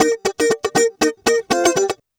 120FUNKY03.wav